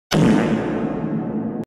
Play Yankılı Osuruk Sesi - SoundBoardGuy
Play, download and share yankılı osuruk sesi original sound button!!!!
yankili-osuruk-sesi.mp3